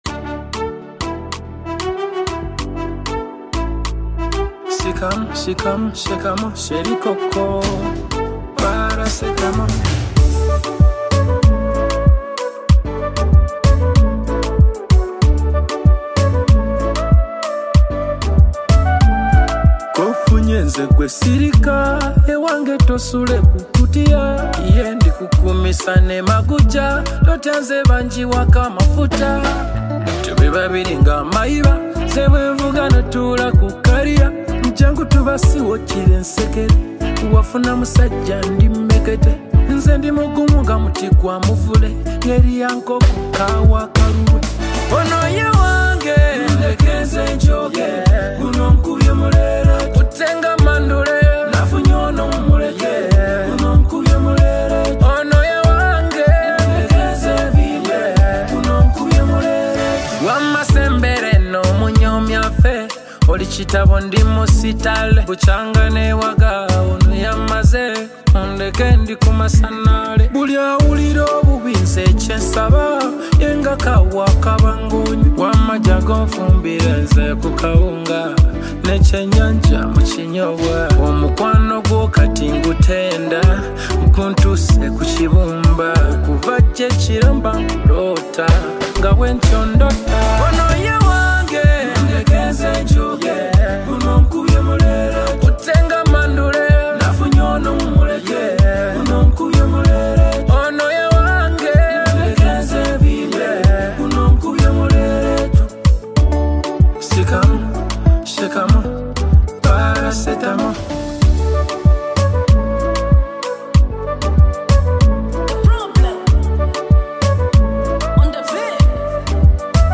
RnB And Afro Beat